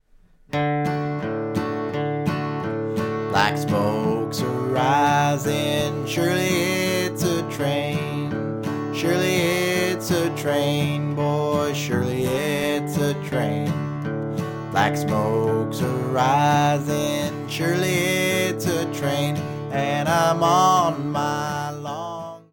Guitar & voice, medium speed (key of D)